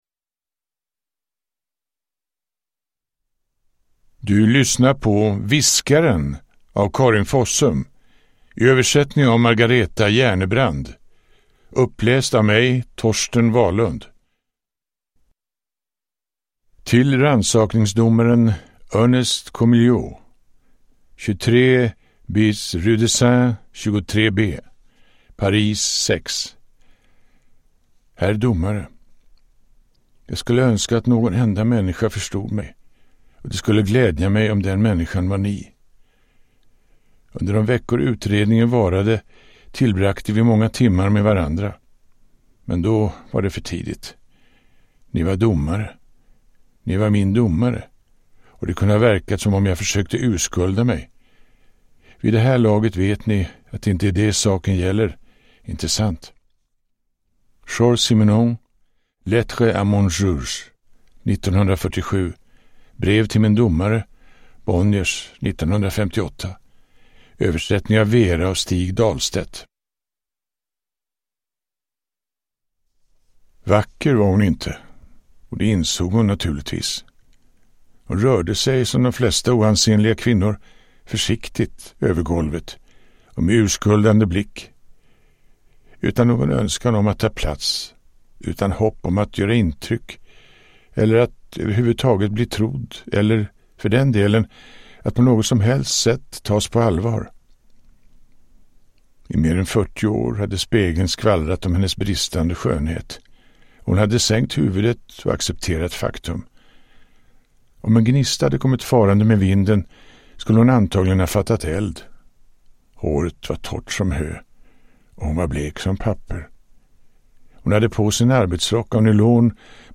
Uppläsare: Torsten Wahlund